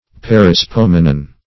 Meaning of perispomenon. perispomenon synonyms, pronunciation, spelling and more from Free Dictionary.
Search Result for " perispomenon" : The Collaborative International Dictionary of English v.0.48: Perispomenon \Per`i*spom"e*non\, n.; pl.